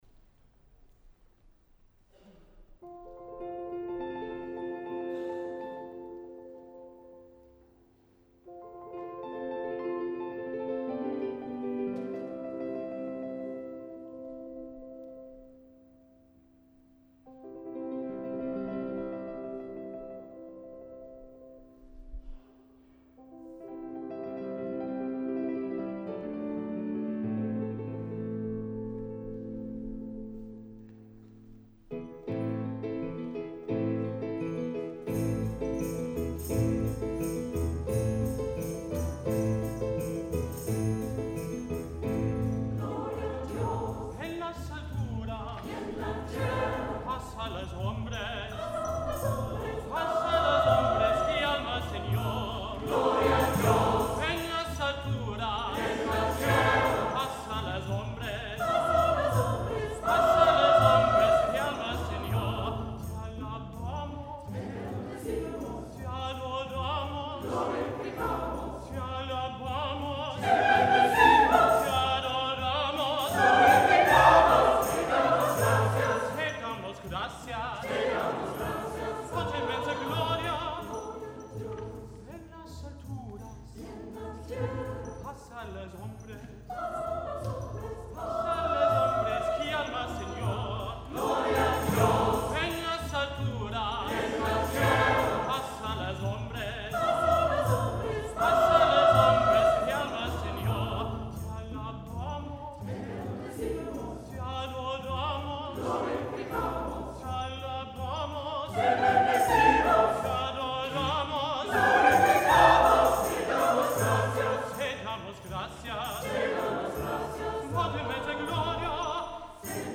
Holy Rood Catholic Church, Abingdon Road, Oxford
organ & piano
tenor